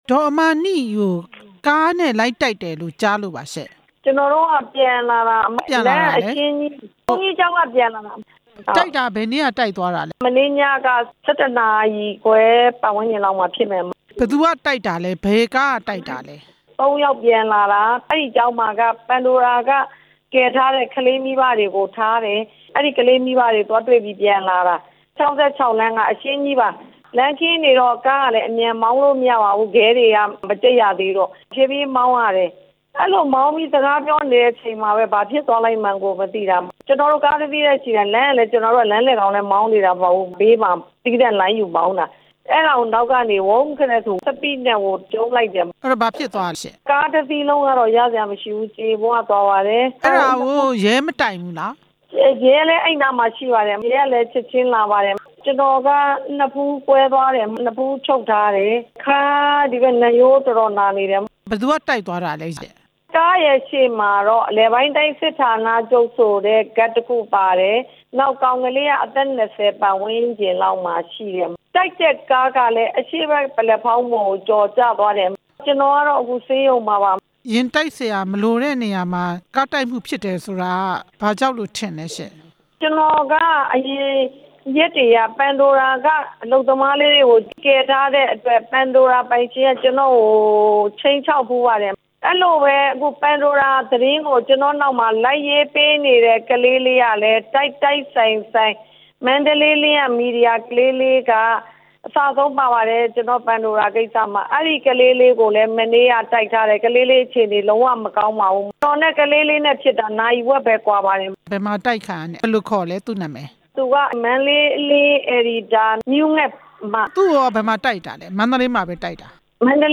မန္တလေးက ယာဉ်တိုက်ခံလိုက်ရတဲ့ ၂ ဦးနဲ့ ပတ်သက်ပြီး မေးမြန်းချက်